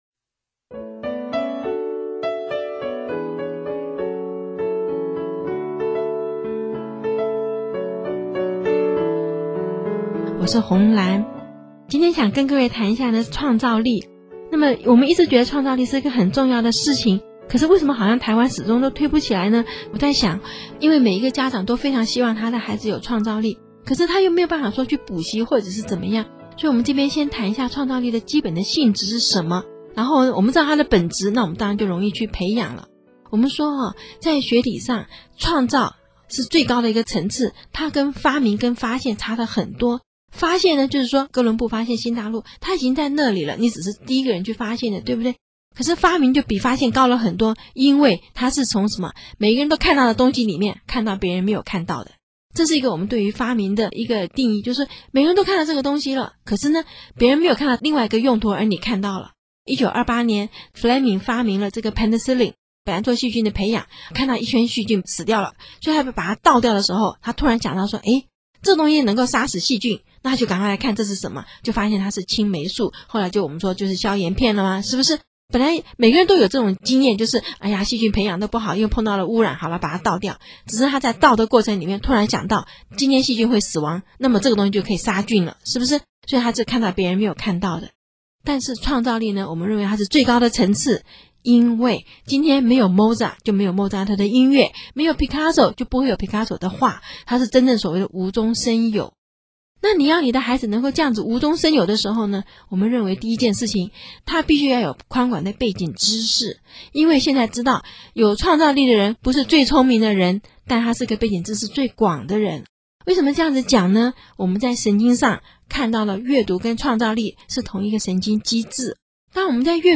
有聲書第四輯